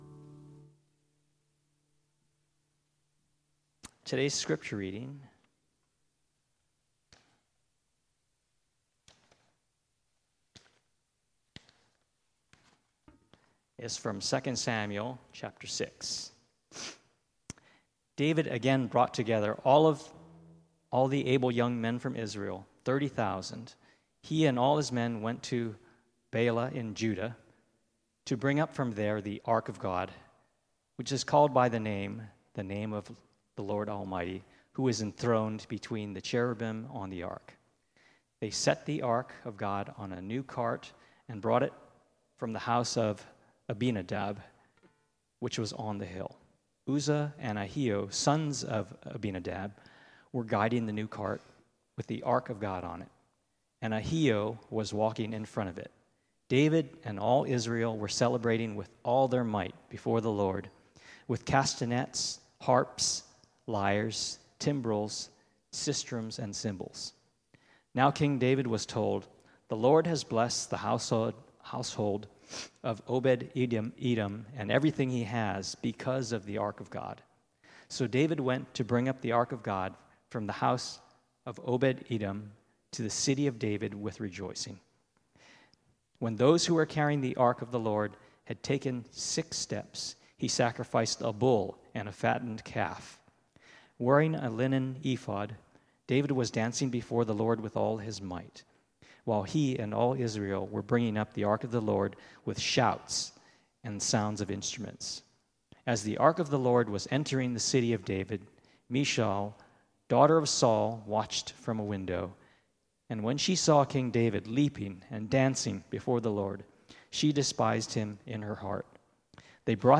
Sermon Notes TEXT: 2 Samuel 6:1-5, 12-19 1David again brought together all the able young men of Israel—thirty thousand. 2 He and all his men went to Baalah in Judah to …